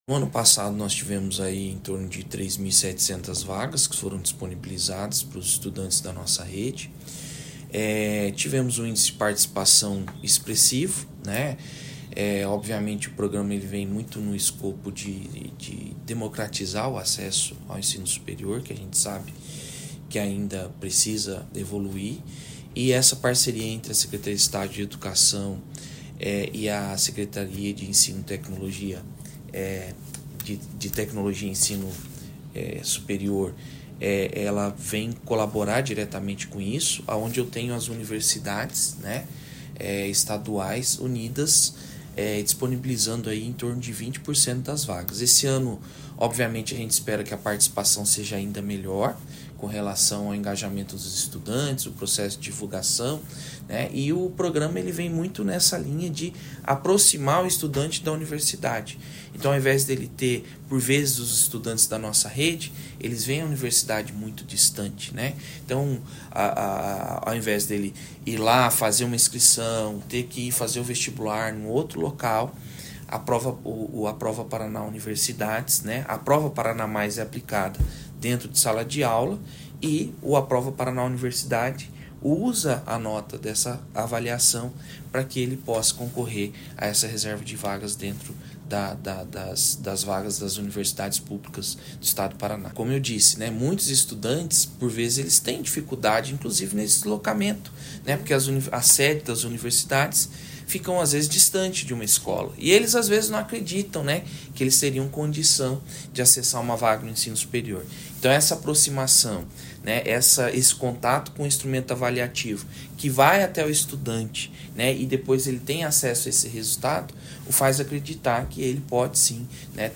Sonora do diretor de Educação da Seed-PR, Anderfabio Oliveira, sobre o programa Aprova Paraná Universidades